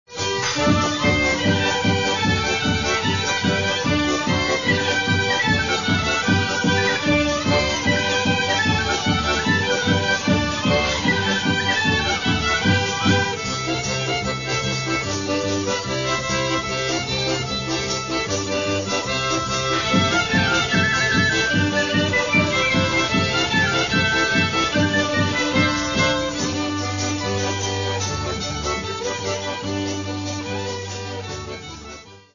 instrumental ensembles
instrumental'na versija